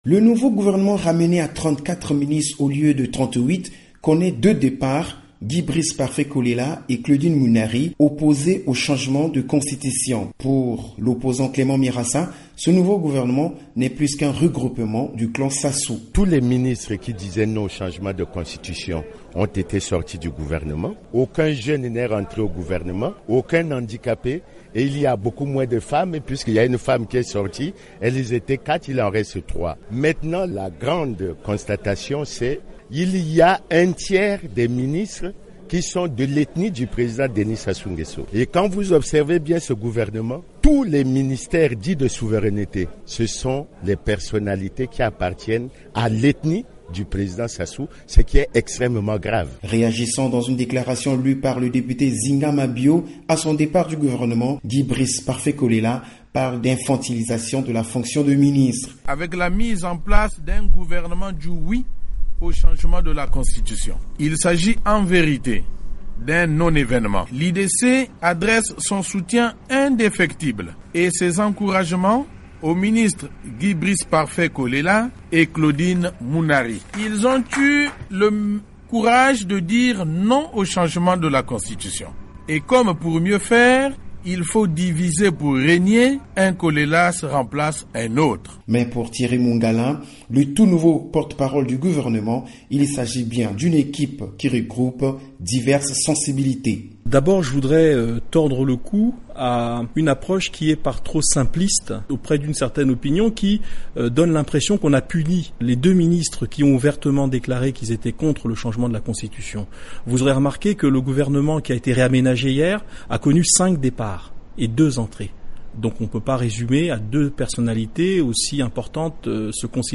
Reportage
à Brazzaville